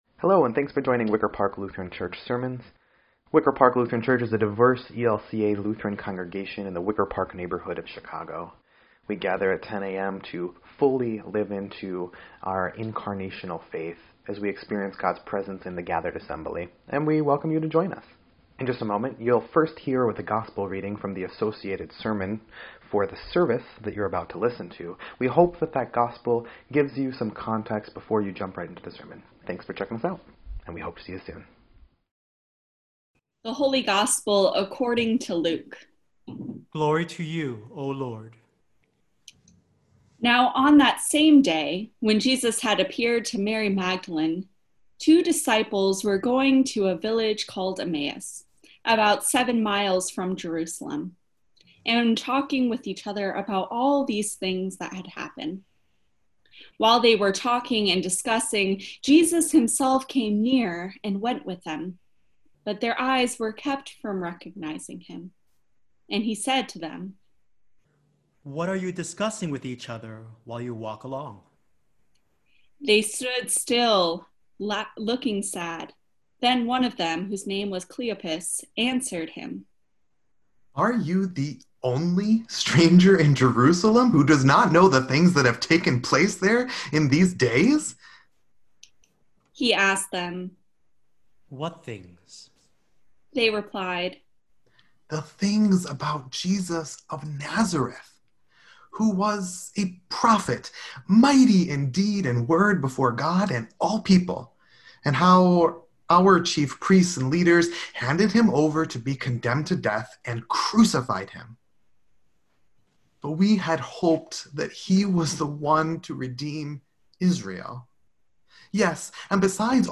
4.26.20-Sermon_EDIT.mp3